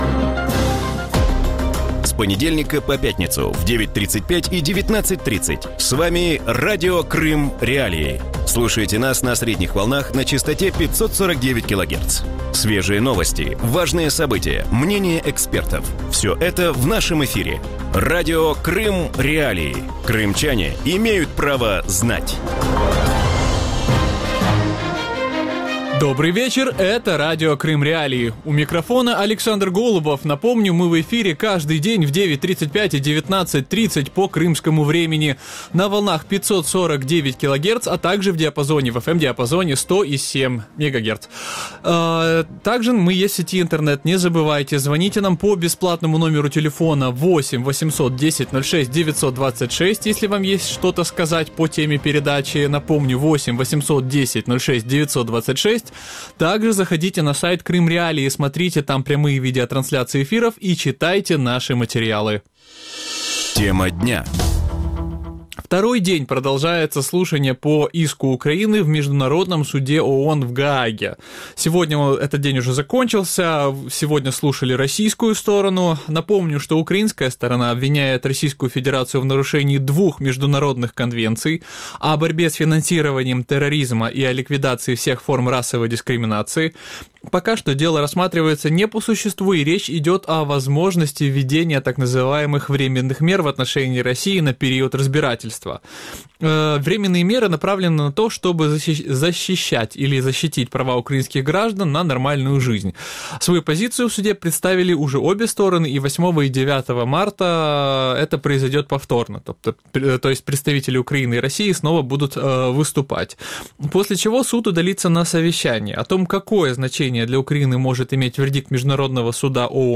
В вечернем эфире Радио Крым.Реалии говорят о ходе слушаний по украинскому иску к России в Международном суде ООН в Гааге. Какие аргументы приводят украинская и российская сторона в свою пользу? Какие последствия может иметь вердикт Международного суда ООН и есть ли возможность взыскать с России компенсацию за нанесенный ущерб?